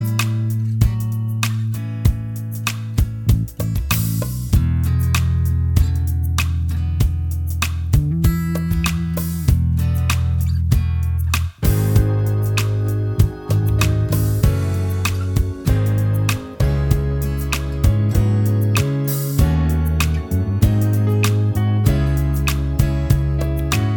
Minus Main Guitar Soft Rock 3:58 Buy £1.50